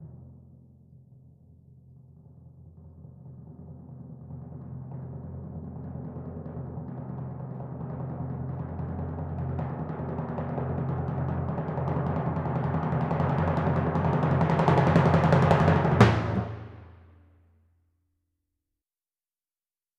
Cinematic 27 Timpani 01.wav